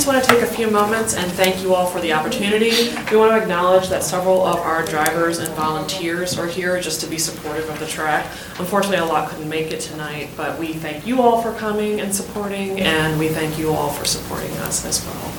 At the January 9th county commissioner meeting, a one-year extension was approved for Mountain Maryland Ventures to run dirt track racing.